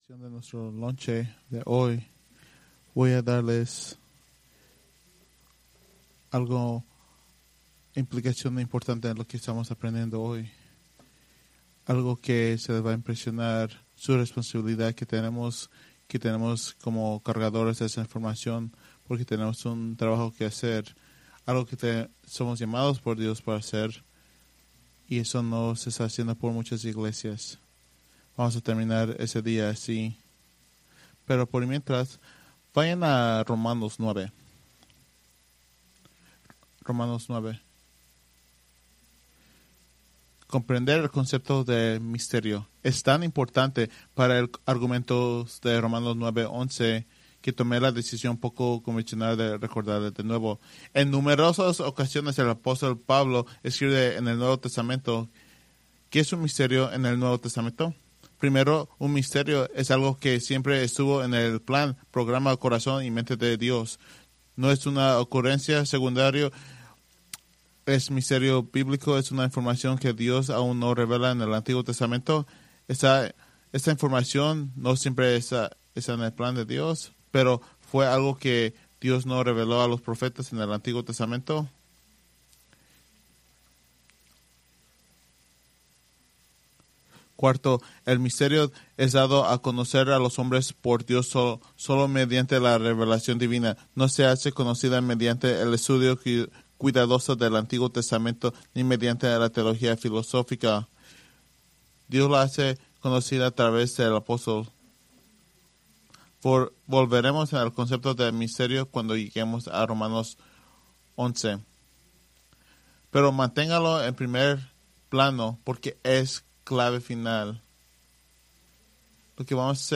Preached April 18, 2026 from Romanos 9:1-29